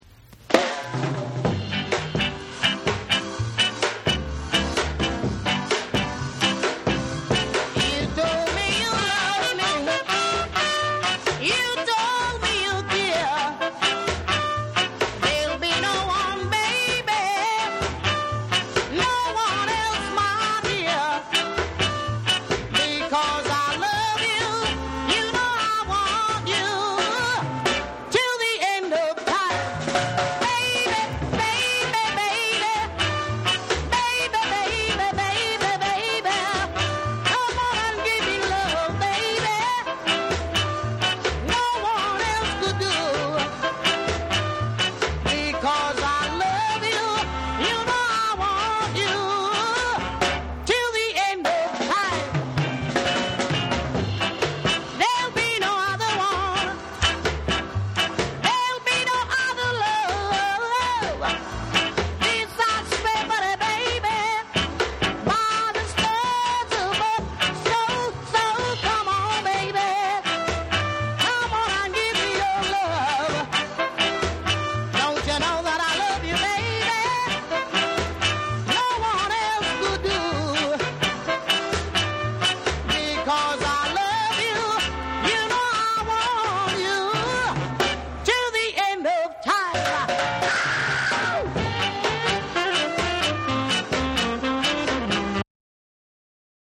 アップ・テンポのスカをバックに初々しいヴォーカルが光る4。弾けるホーンがダンサブルなリズム＆ブルース・ナンバー5。
※ジャマイカ盤特有のチリノイズあり。
REGGAE & DUB